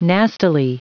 Prononciation du mot nastily en anglais (fichier audio)
Prononciation du mot : nastily